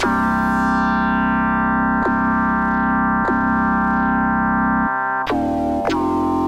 描述：原声带
标签： 吉他 贝斯 合成器 视频音乐 电影音乐 摇滚 器乐
声道立体声